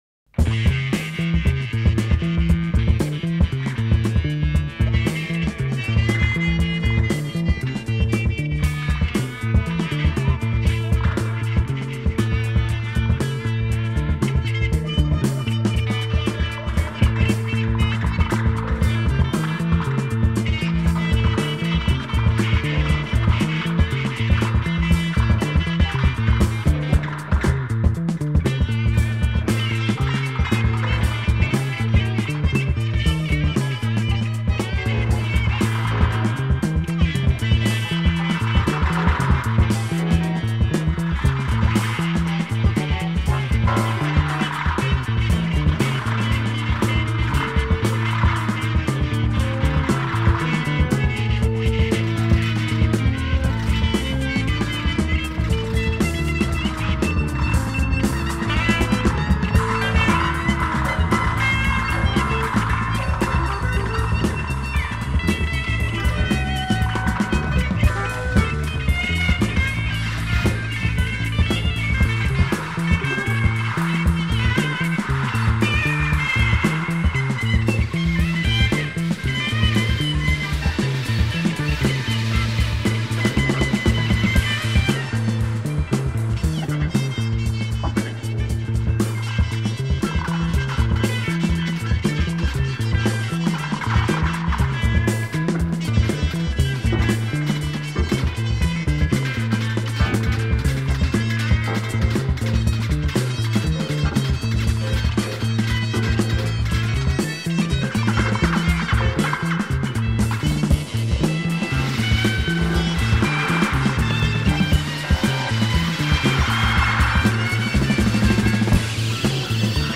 موسیقی فیلم